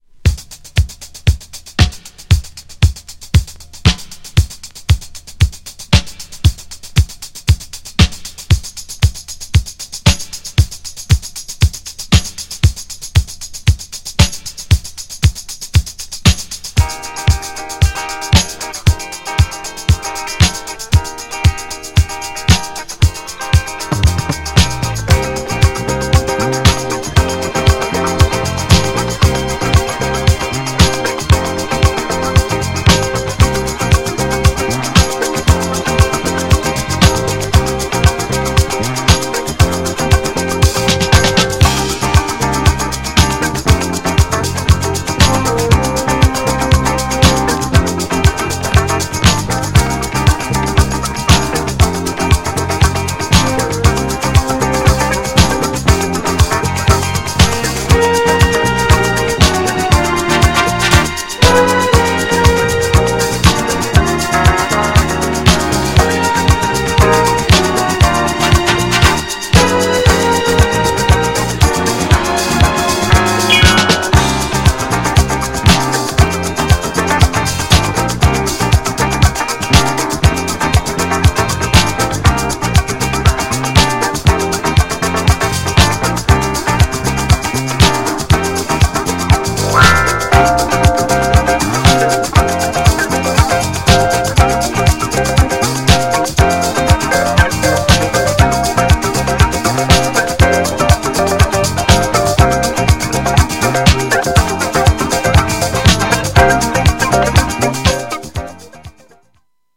LOFT CLASSICとしても人気のFUSIONダンス!!
GENRE Dance Classic
BPM 111〜115BPM